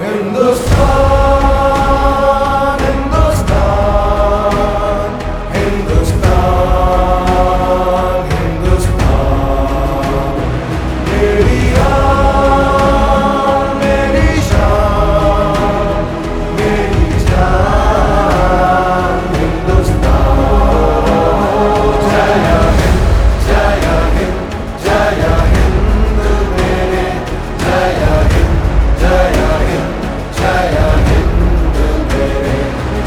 Patriotic Bollywood ringtone